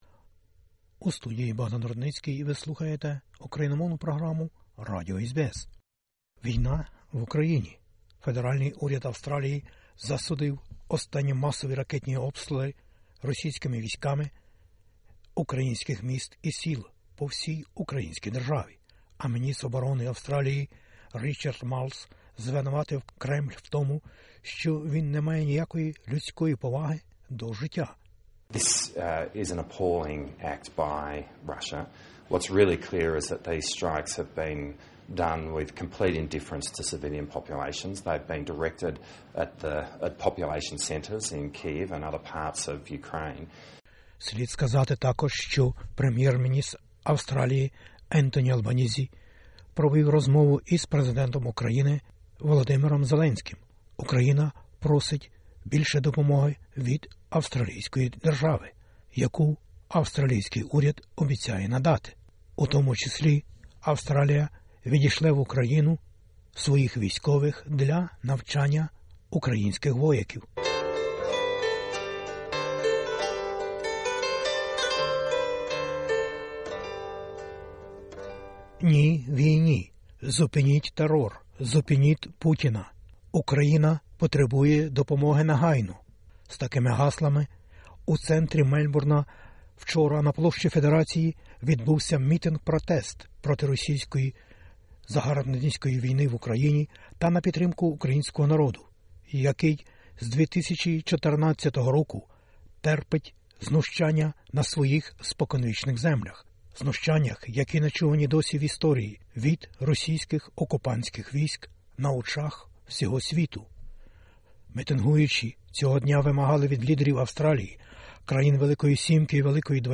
У центрі Мельбурна, на Площі Федерації, 11 жовтня 2022-го року відбувся мітинґ-протест проти російської війни в Україні та на підтримку українського народу, який з 2014- року терпить на своїх споконвічних землях нечувані досі знущання від російських окупанських військ на очах всього світу. Мітинґуючі вимагали від лідерів Австралії, країн G7 i G20 та світу допомогти припинити кровопролиття і свавілля російських збройних сил, як зазначали промовці та організатори цієї протестної акції...